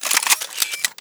mach_slide.wav